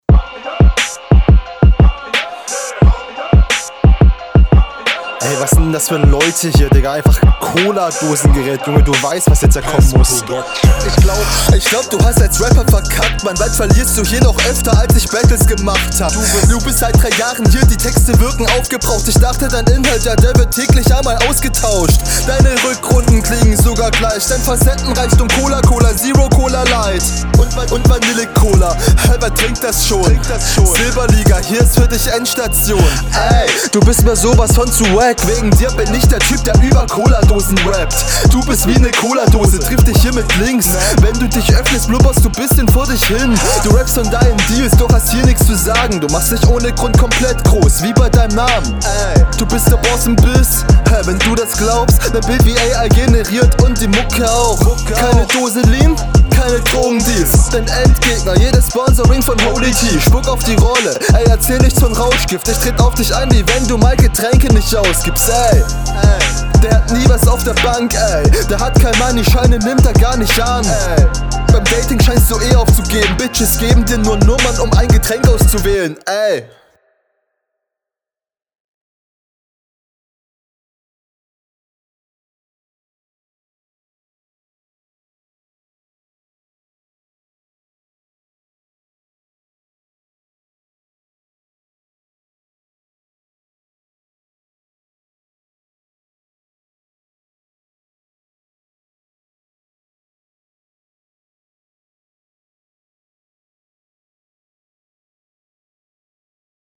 Das doppelte Adlib bei 0:33 kommt irgendwie überlst geil, die "Stuttereffects" dafür nicht so.